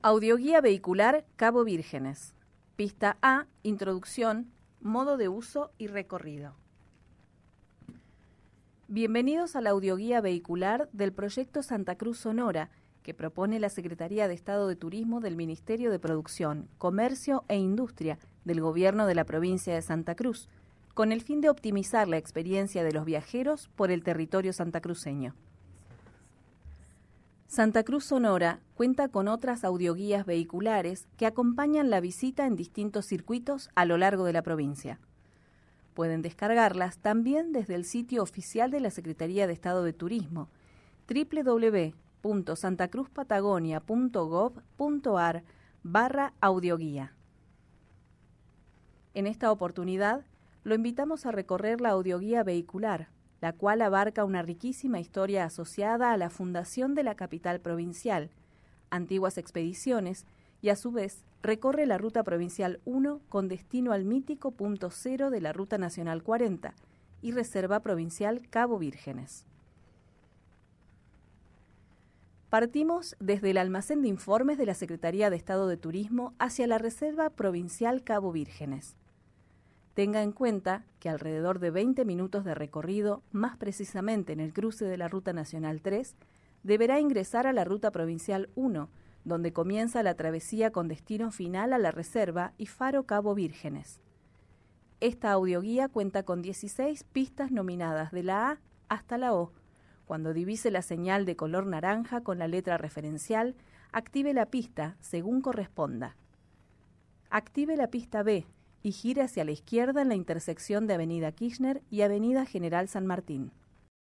Audioguía Vehicular Reserva Provincial Cabo Vírgenes
• La audioguía comprende una introducción (PISTA A) y dieciséis pistas de audio que se organizan de B a P.
Audioguia_Cabo_Virgenes-Pista-A.mp3